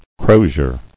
[cro·zier]
Cro"zier (kr?"zh?r), n. See Crosier.